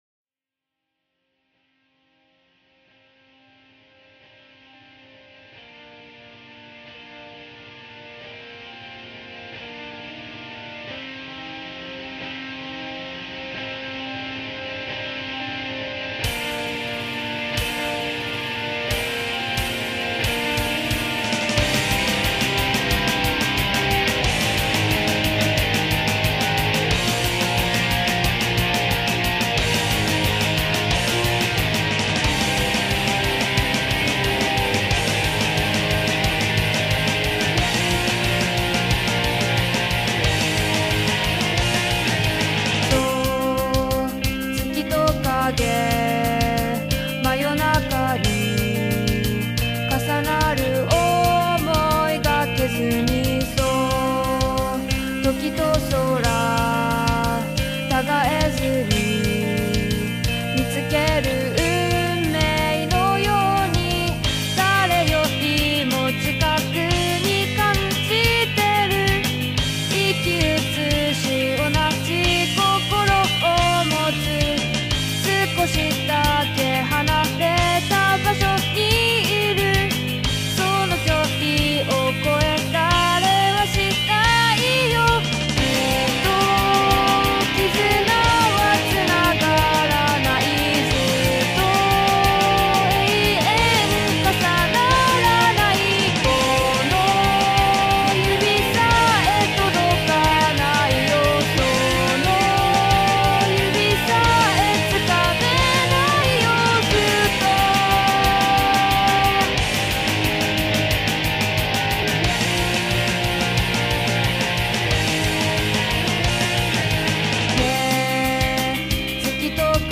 AI歌唱
※まだ上手く調整出来ていないので、少し棒読みな歌い方になっています。